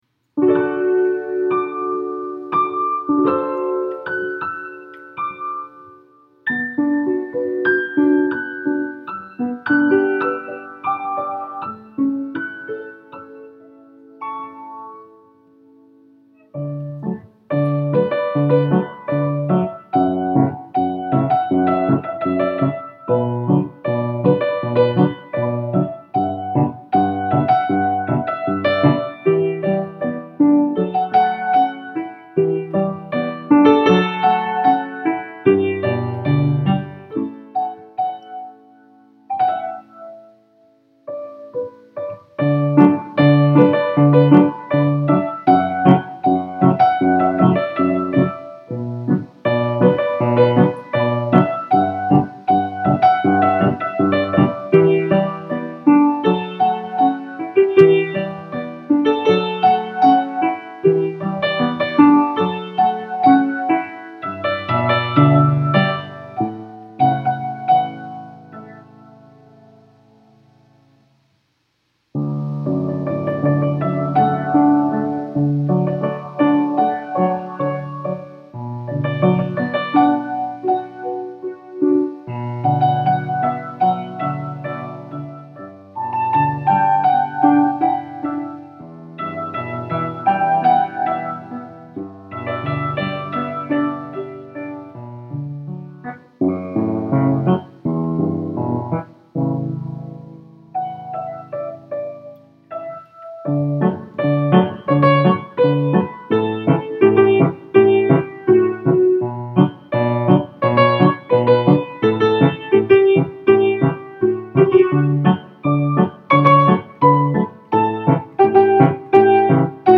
” but arranged as if it’s a fifties musical movie love song